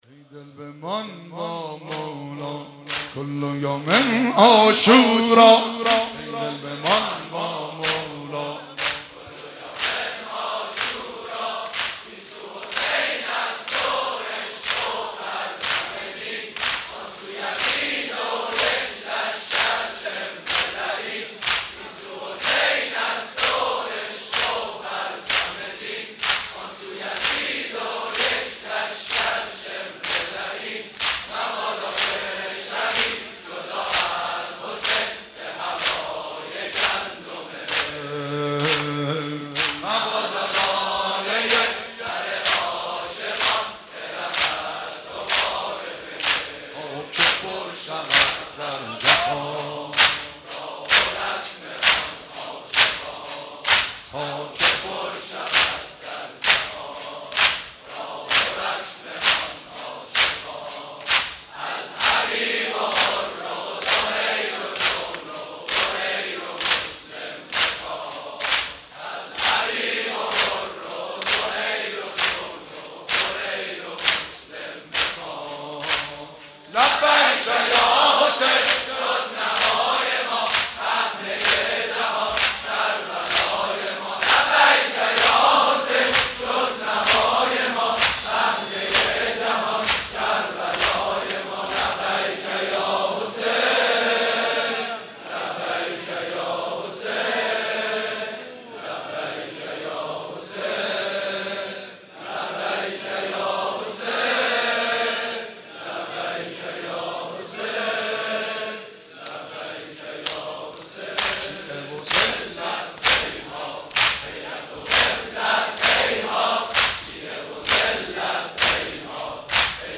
شب عاشورا
8 سرود پایانی